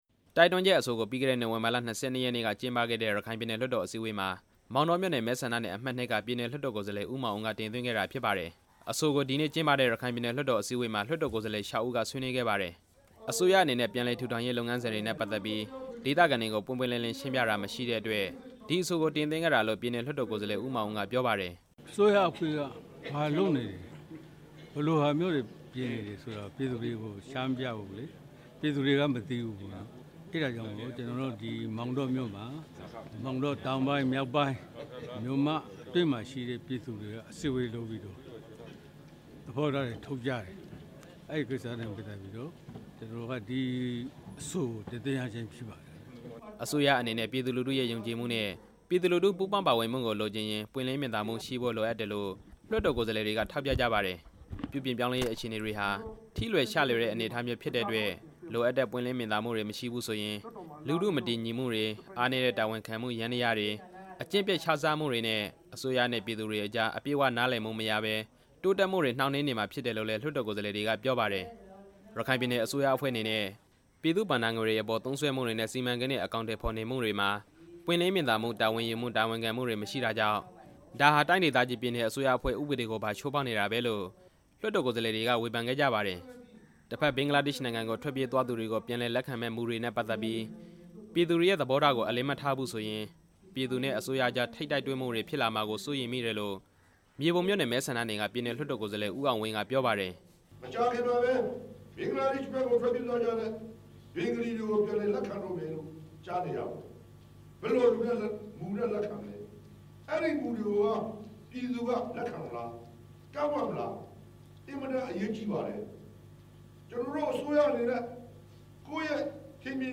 ရခိုင်လွှတ်တော်အစည်းအဝေး တင်ပြချက်